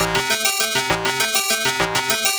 Index of /musicradar/8-bit-bonanza-samples/FM Arp Loops
CS_FMArp C_100-A.wav